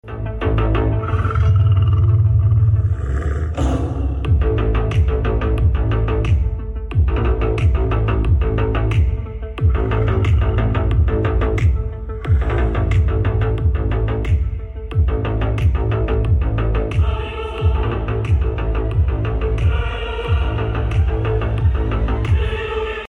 Three-way subwoofer